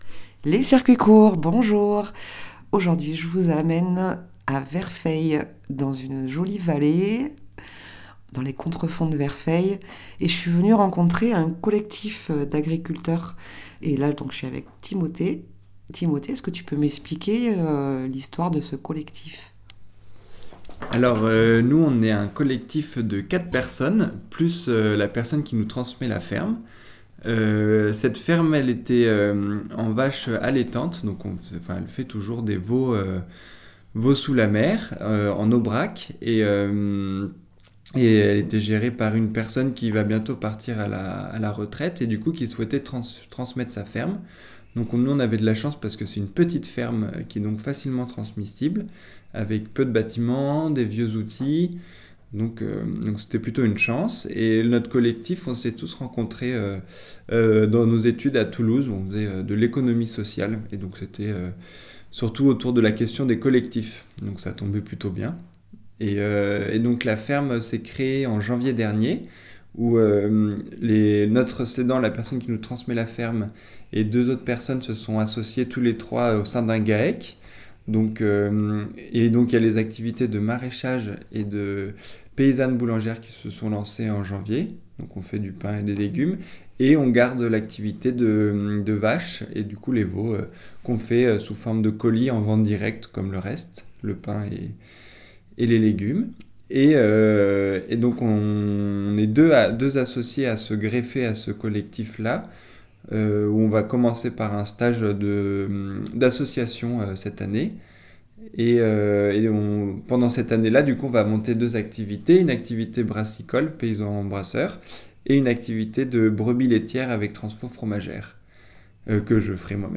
Une visite du GAEC de la Vaysse, à Verfeil sur Seye, reprise récemment en collectif.